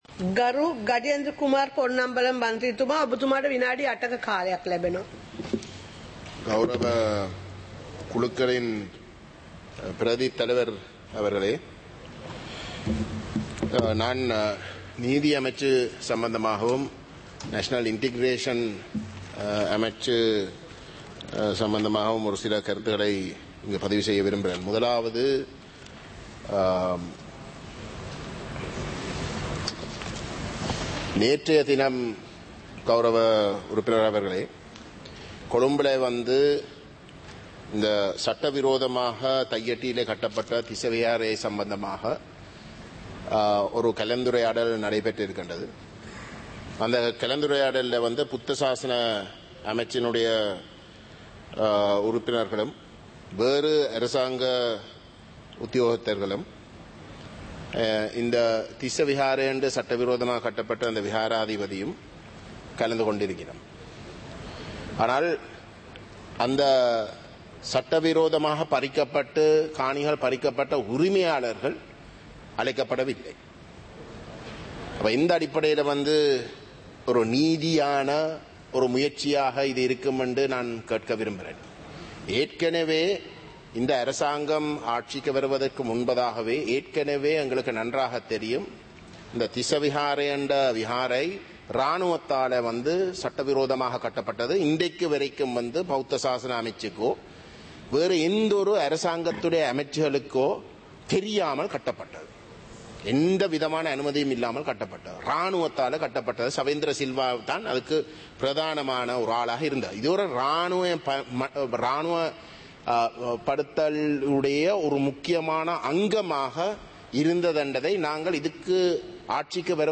சபை நடவடிக்கைமுறை (2026-02-19)
நேரலை - பதிவுருத்தப்பட்ட